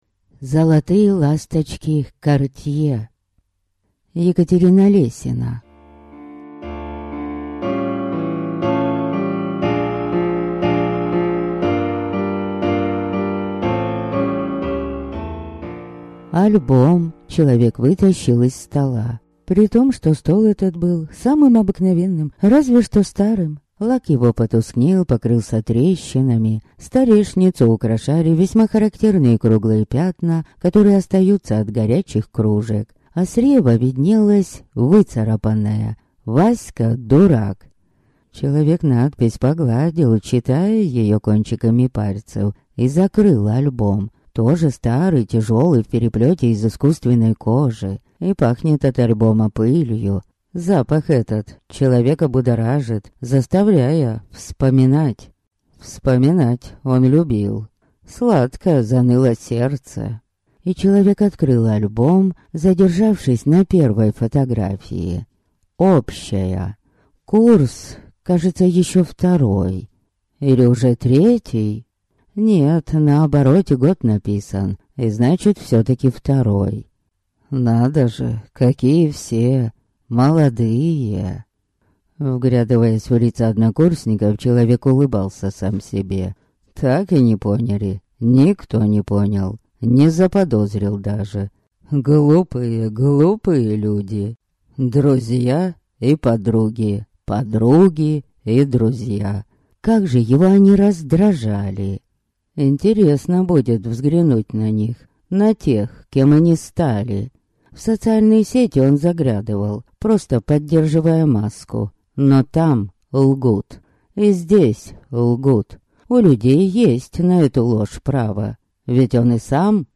Аудиокнига Золотые ласточки Картье | Библиотека аудиокниг